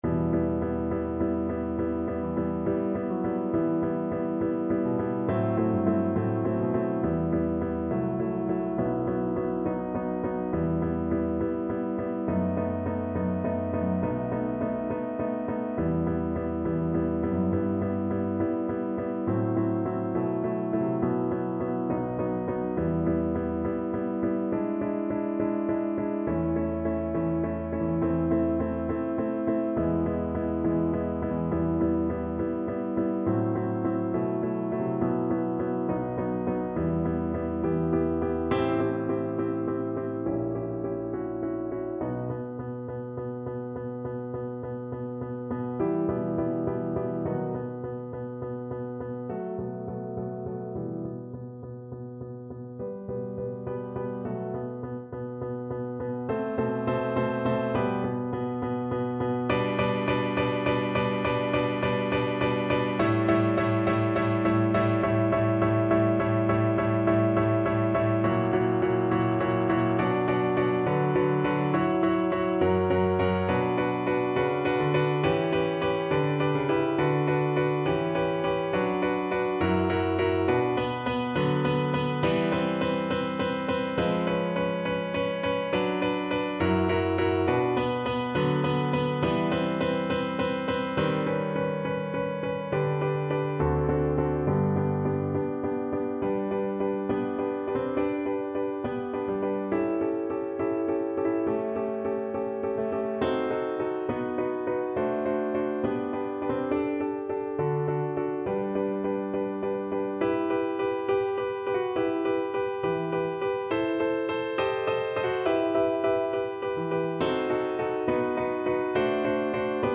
Voice Classical
Piano Playalong MP3